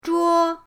zhuo1.mp3